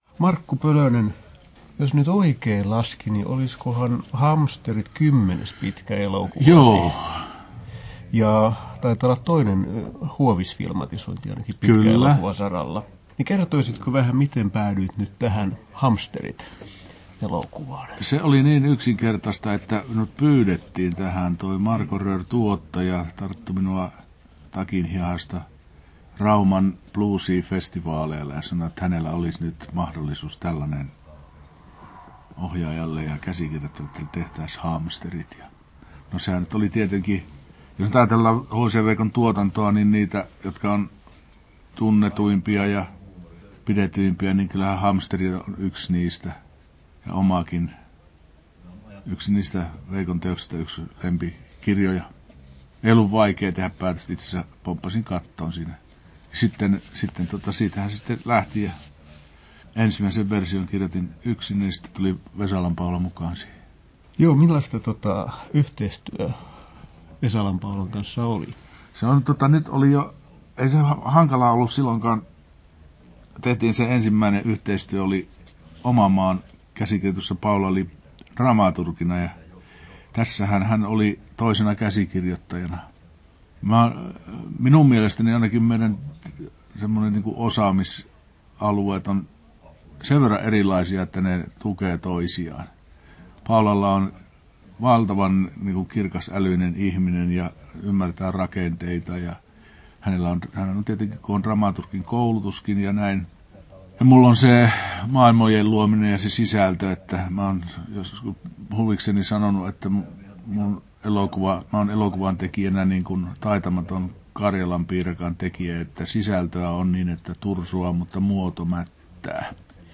Haastattelussa Markku Pölönen Kesto: 20'18" Tallennettu: 12.12.2022, Turku Toimittaja